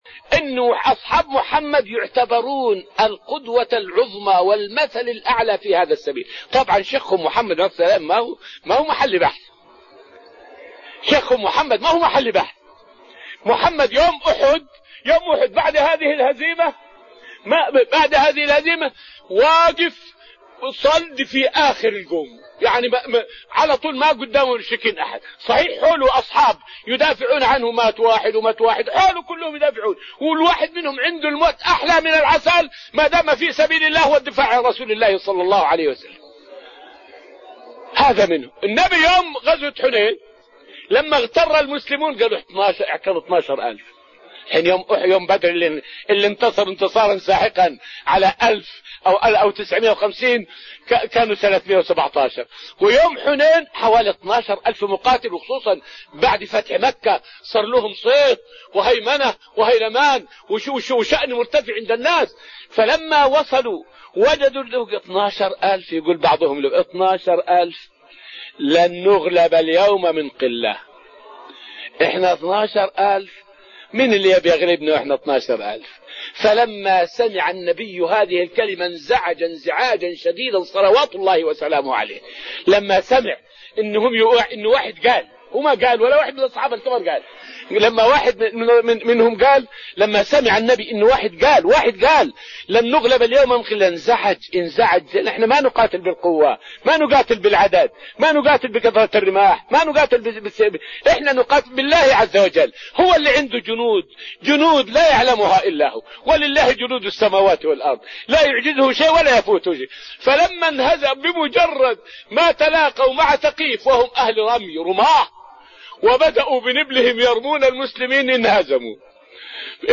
فائدة من الدرس الثاني عشر من دروس تفسير سورة القمر والتي ألقيت في المسجد النبوي الشريف حول ثبات الصحابة عند سماعهم بخبر جموع المشركين بعد غزوة أحد.